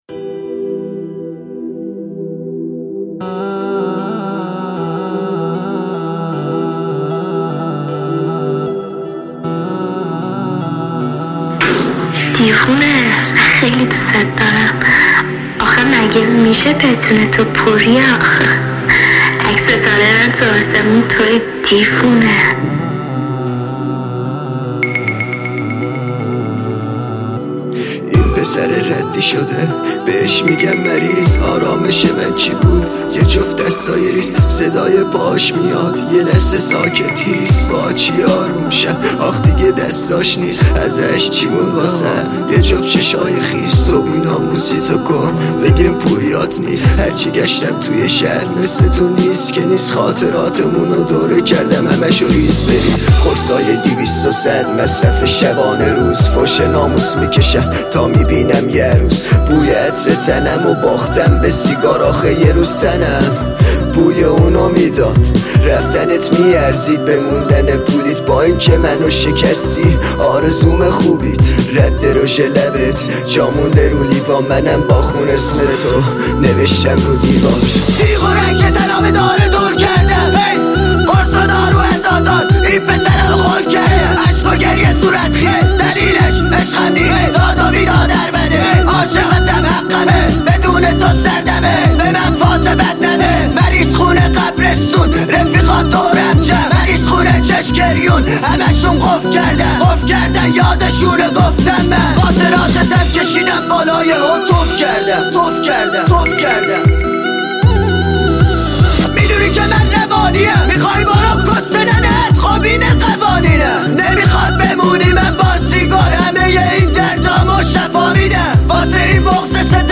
دانلود آهنگ های پیشنهادی دیس لاو